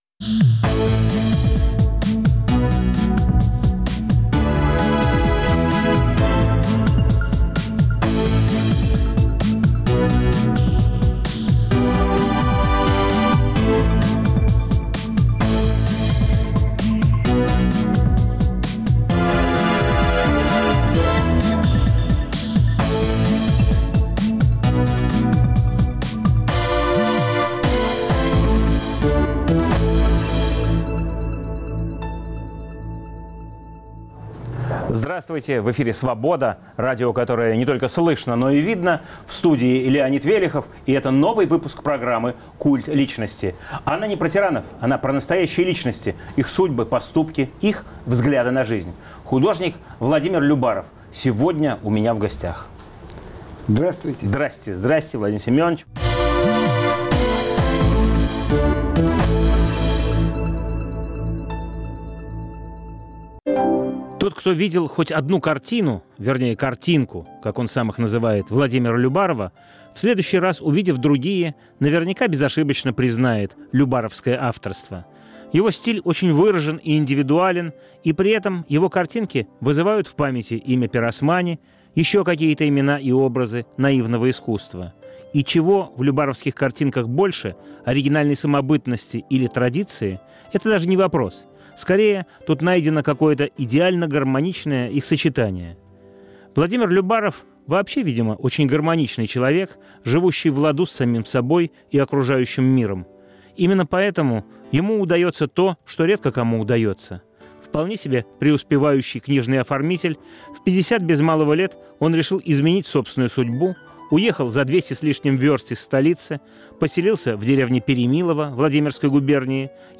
Новый выпуск программы о настоящих личностях, их судьбах, поступках и взглядах на жизнь. В студии художник Владимир Любаров. Ведущий - Леонид Велехов.